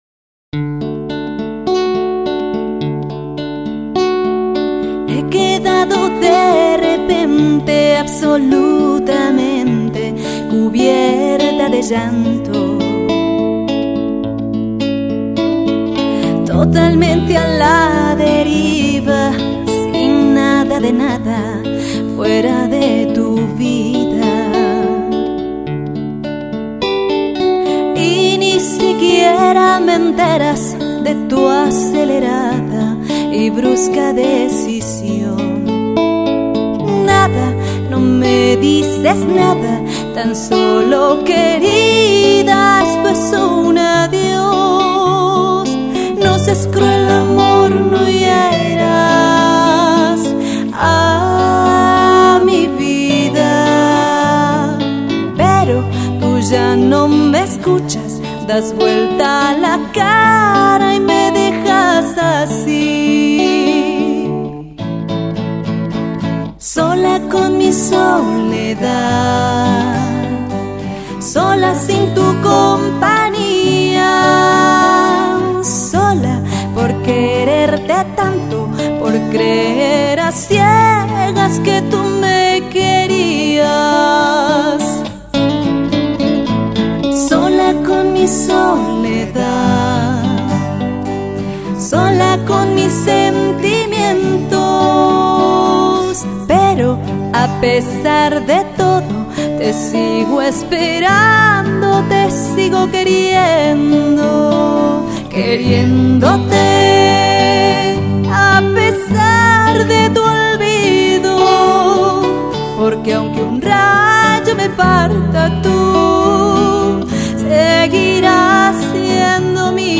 de una manera acústica
guitarra